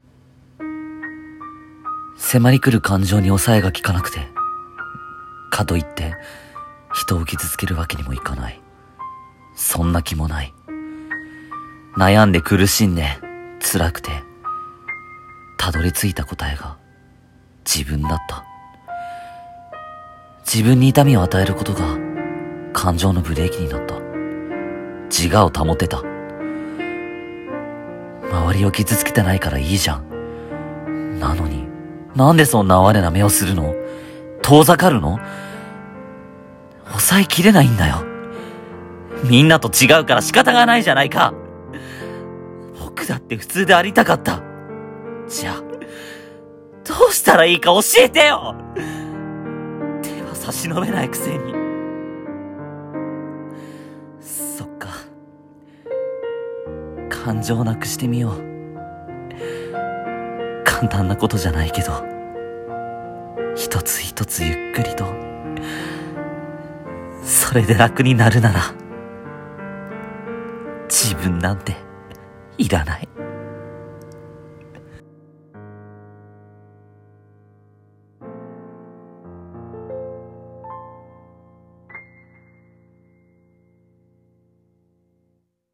【一人声劇】